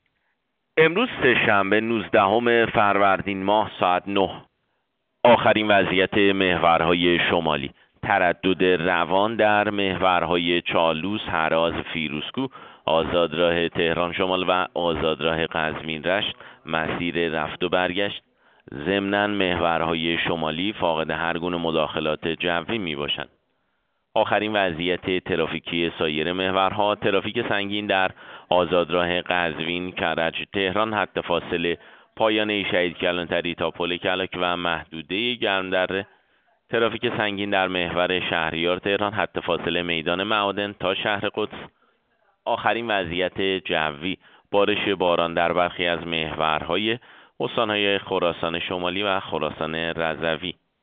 گزارش رادیو اینترنتی از آخرین وضعیت ترافیکی جاده‌ها ساعت ۹ نوزدهم فروردین؛